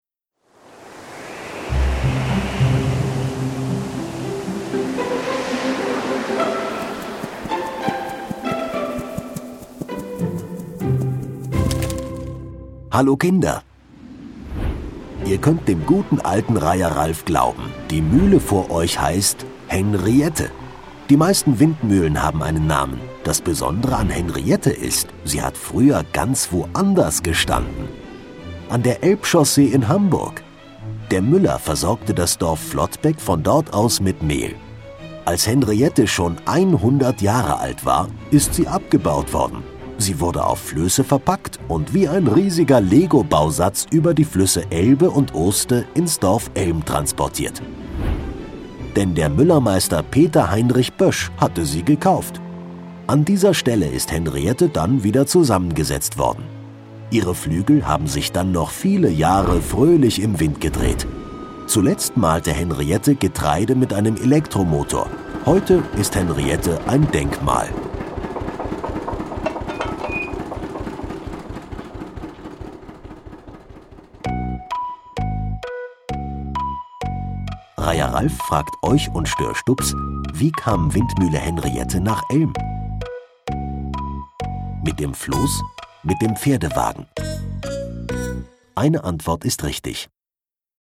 Windmühle Henriette - Kinder-Audio-Guide Oste-Natur-Navi